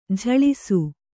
♪ jhaḷasu